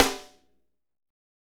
Index of /90_sSampleCDs/Northstar - Drumscapes Roland/SNR_Snares 1/SNR_H_H Snares x